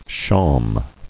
[shawm]